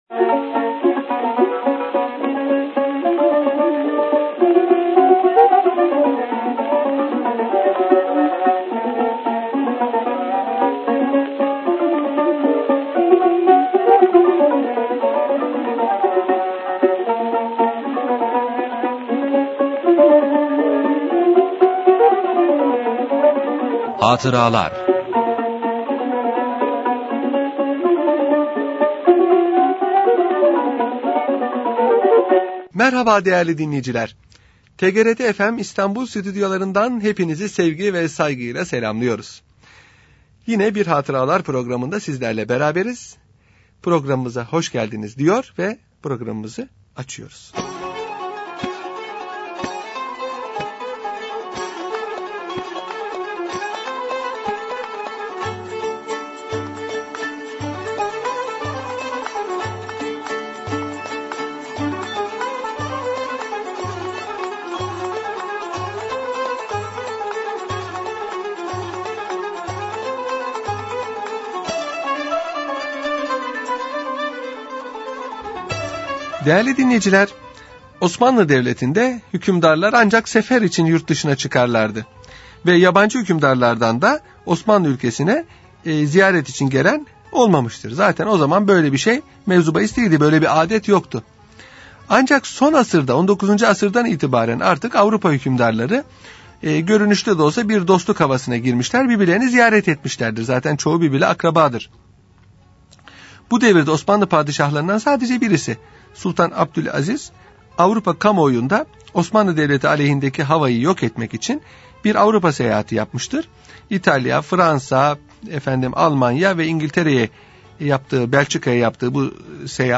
Radyo Programi - Avusturya Kralının Ziyareti